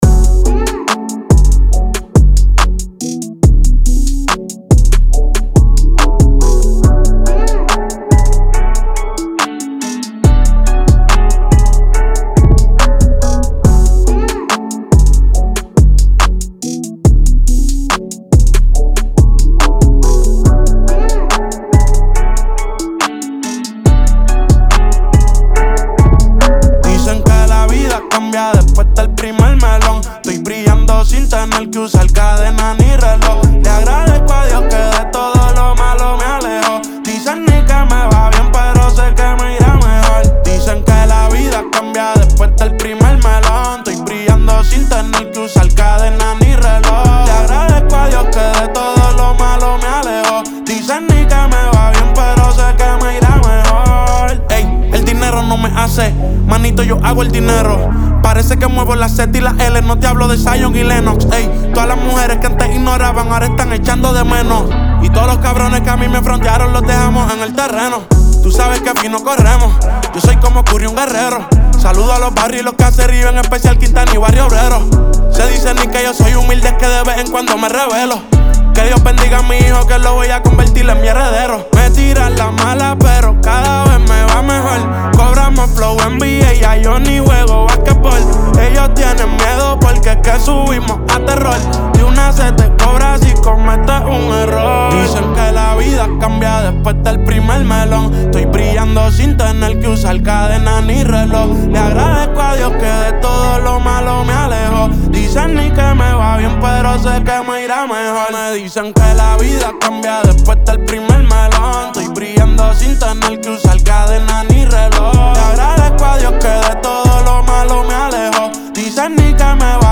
Genre: Latin Trap.